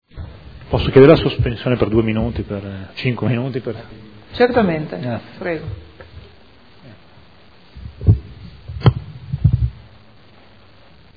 Seduta del 30/10/2014. Chiede sospensione lavori